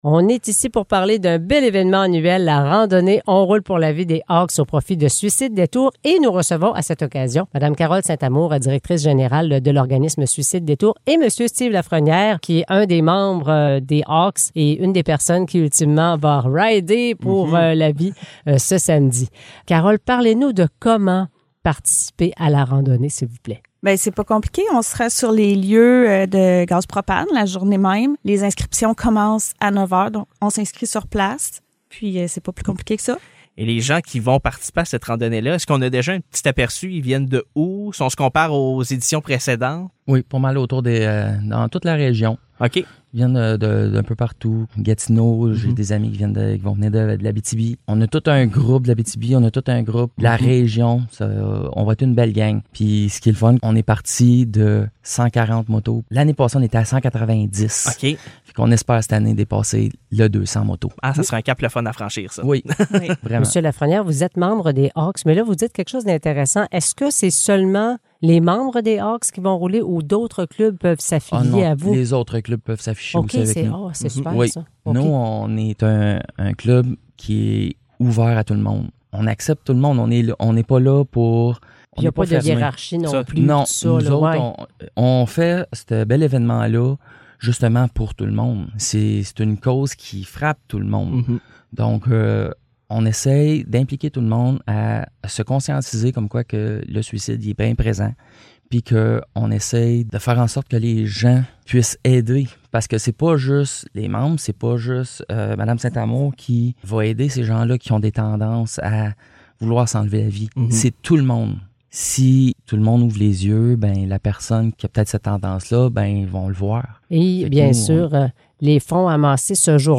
Entrevue sur la randonnée On roule pour la vie
entrevue-sur-la-randonnee-on-roule-pour-la-vie.mp3